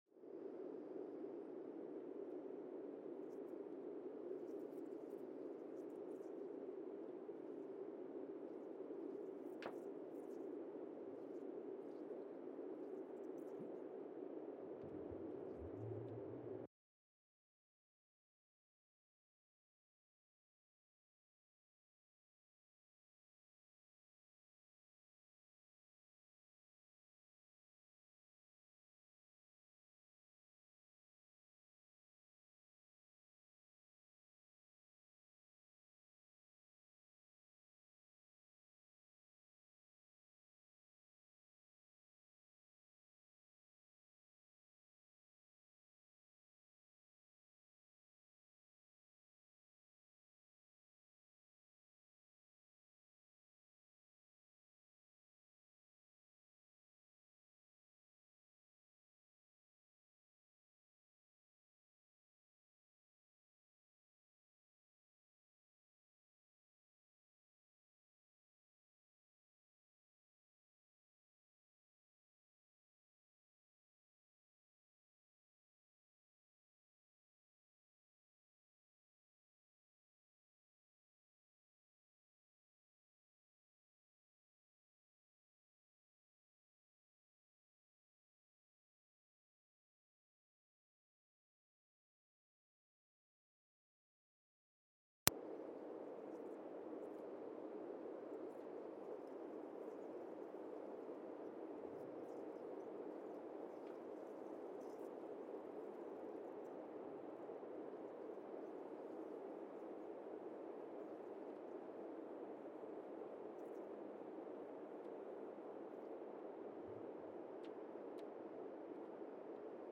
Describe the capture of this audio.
Sensor : Teledyne Geotech KS-54000 borehole 3 component system Recorder : Quanterra Q330HR @ 20 Hz Speedup : ×1,800 (transposed up about 11 octaves) Loop duration (audio) : 05:36 (stereo) SoX post-processing : highpass -2 90 highpass -2 90